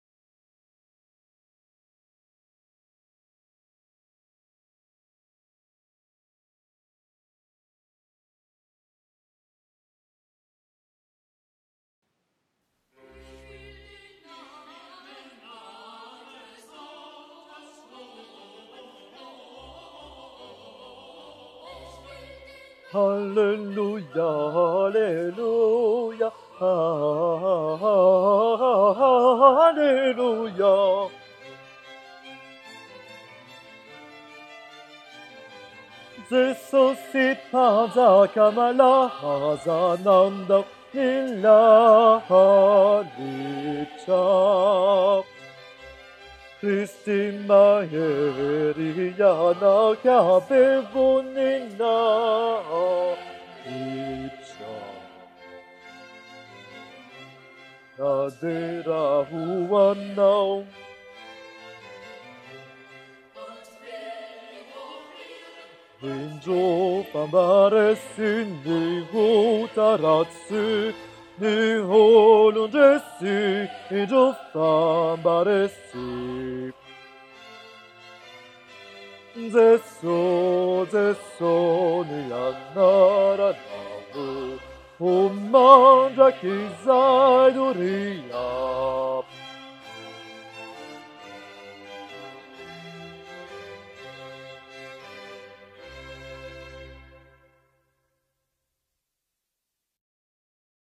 cantate_142_basse-23_04_2014-21-37.mp3